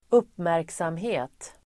Uttal: [²'up:märksamhe:t]